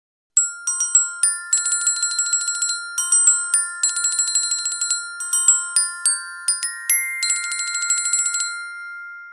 » колокольчики Размер: 148 кб